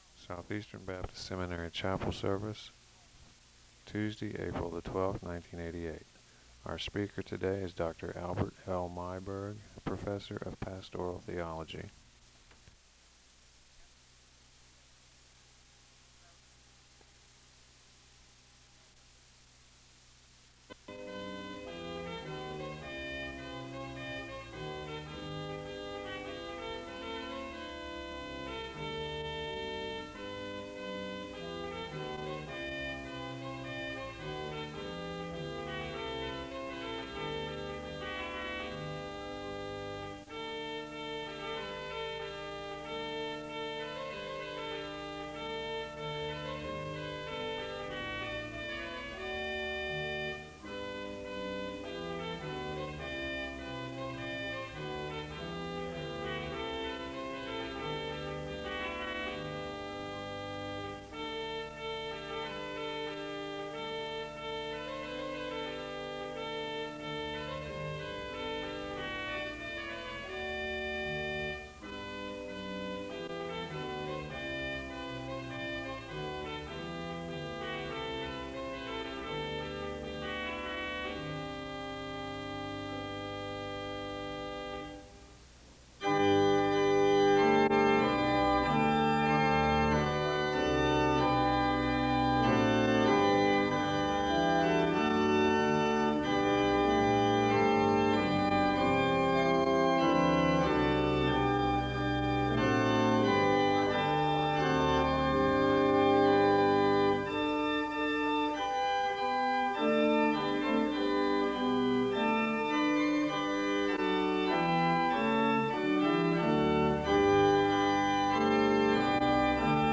The service begins with organ music (0:00-2:41). There is a Scripture reading (2:42-3:15). There is a Scripture reading from Psalms and a moment of prayer (3:16-6:15).
The service concludes with a benediction (22:33-23:21).